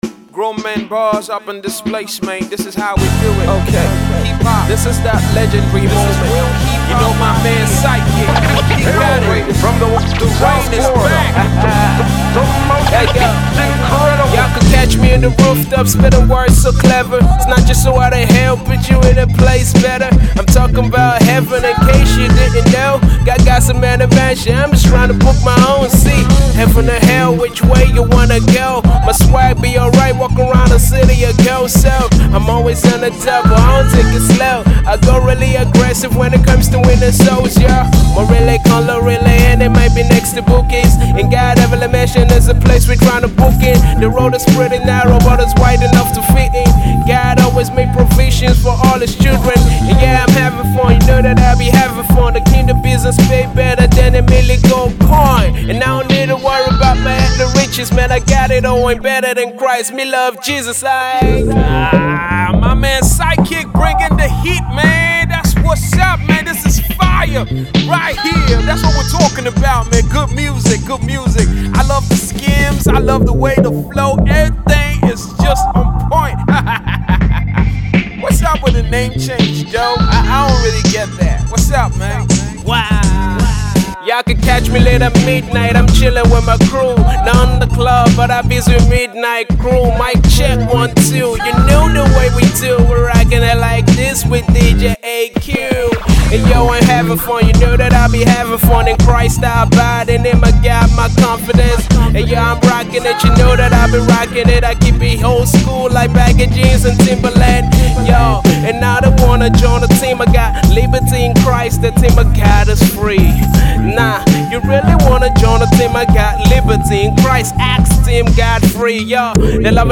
classic Hip-hop sound